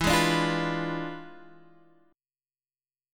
E7b9 chord